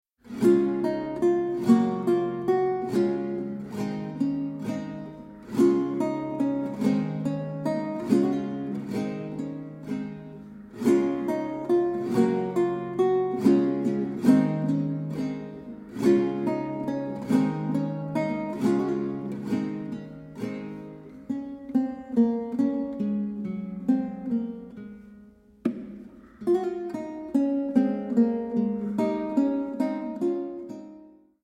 Renaissance and Baroque Guitar
Evropská kytarová hudba z 16. a 17. století
Kaple Pozdvižení svatého Kříže, Nižbor 2014